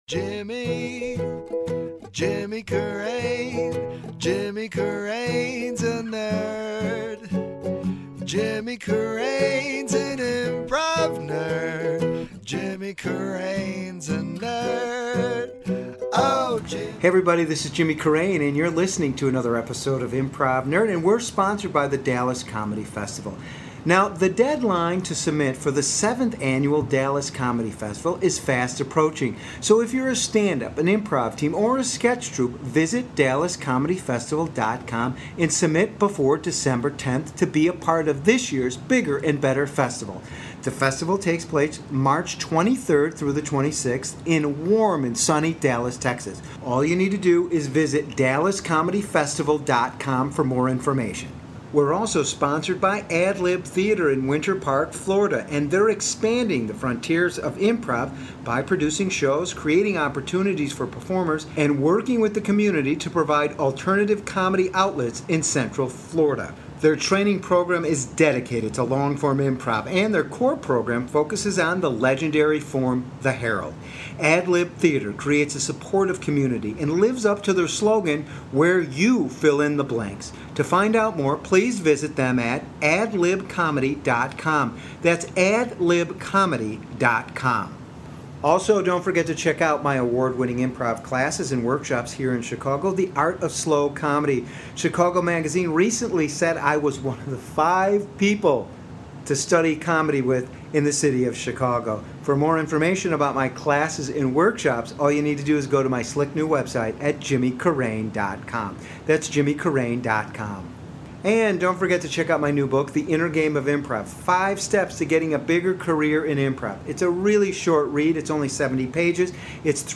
at the Tampa Improv Festival